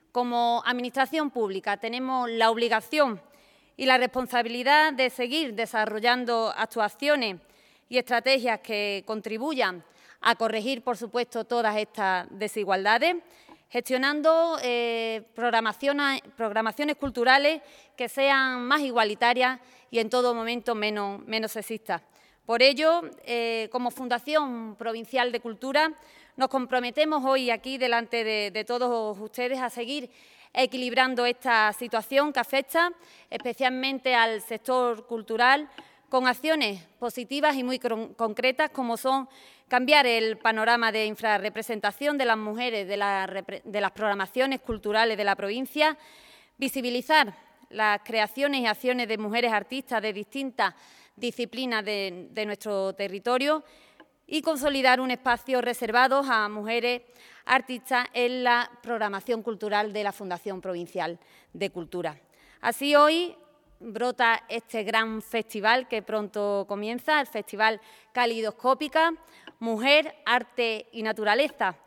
Vanesa Beltrán presenta este programa que se desarrollará en cuatro localidades durante los meses de octubre y noviembre